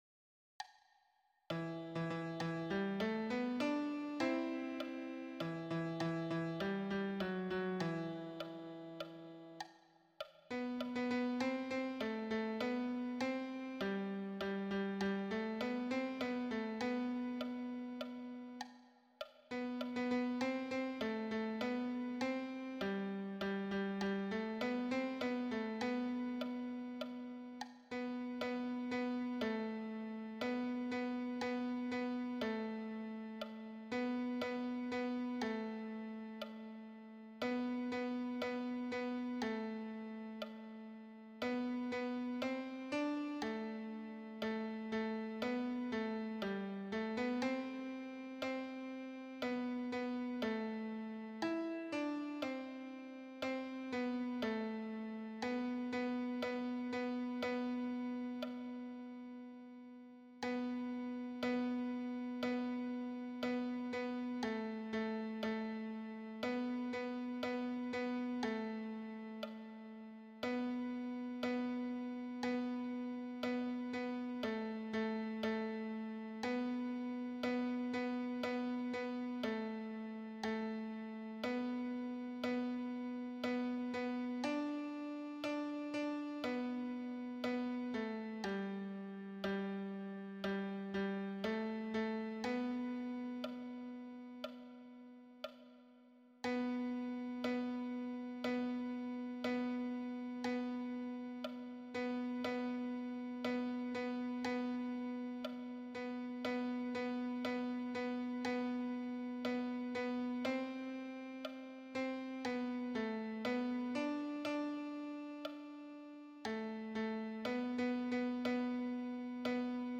Répétition SATB par voix
Messe Saint Germain_Gloria_tenor.mp3